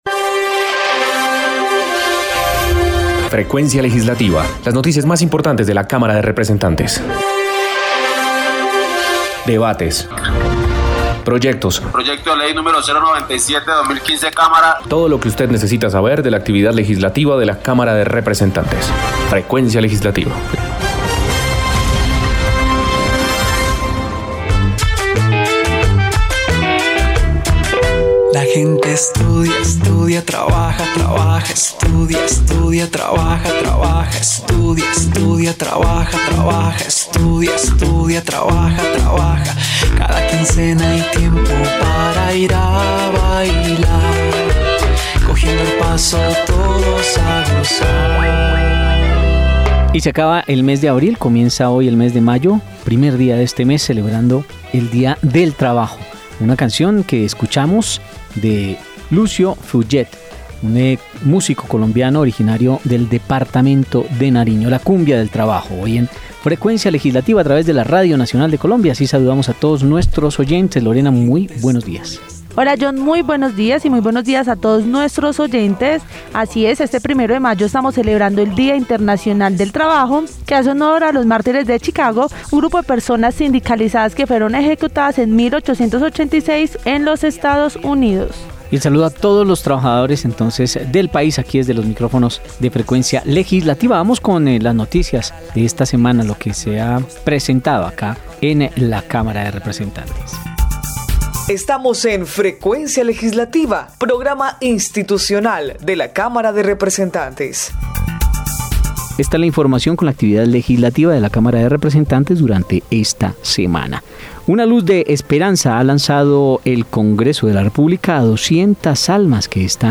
Programa Radial Frecuencia Legislativa. Sábado 1 de Mayo de 2021.